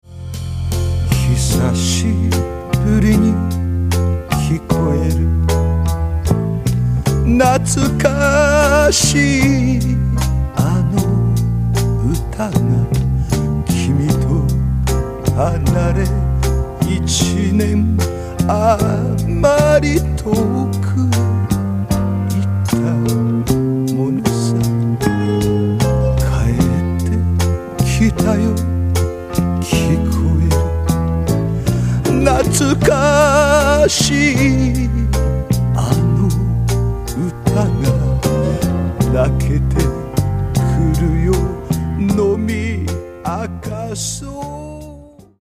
ジャンル：フォーク